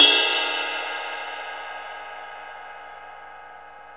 CYM XRIDE 4E.wav